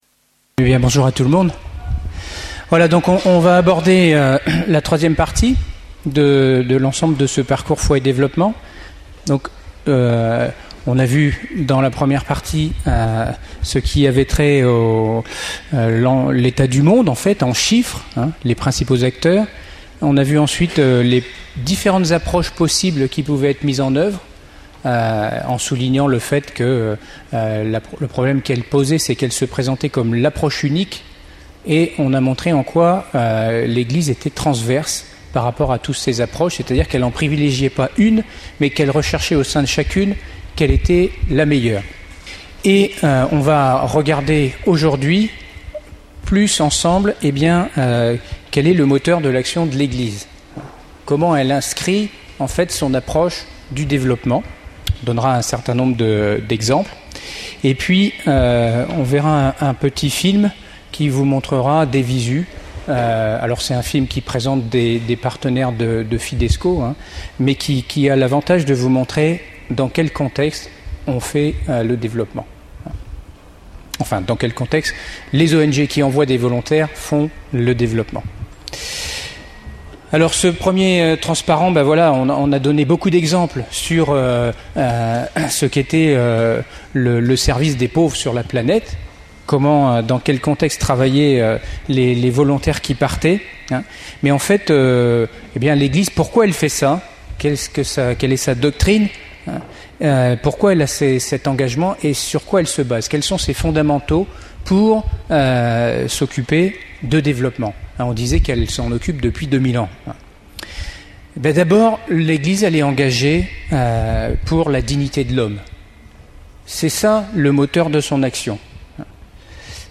Session Familles et Tous : R�pandre l'amour qui nous fait vivre (Beno�t XVI) Paray-le-Monial du 18 au 23 juillet 2009) Parcours Foi et D�veloppement Enregistr� le 22 juillet 2009. Depuis 1975, lors des Sessions d'�t� de Paray-le-Monial, la Communaut� de l'Emmanuel se met au service de tous ceux qui d�sirent faire cette exp�rience du Coeur.